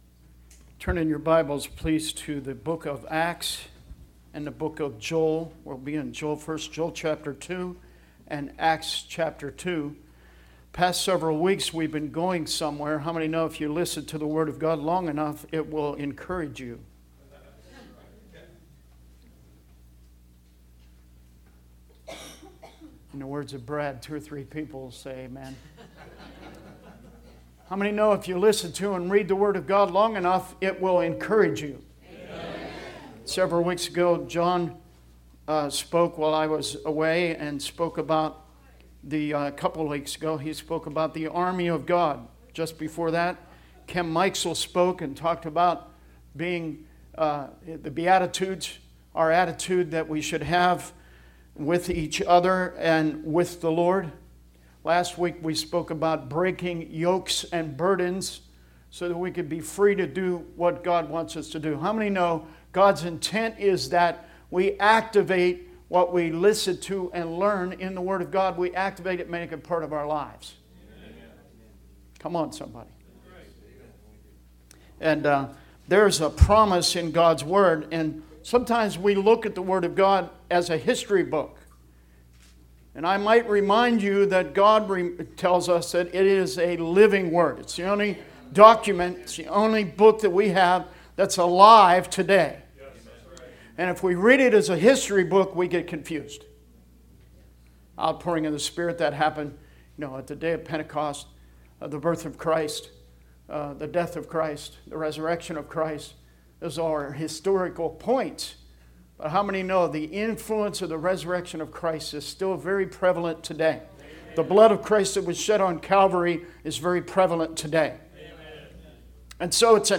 Sermon messages available online.
Joel 2:23-28 Service Type: Sunday Teaching Bible Text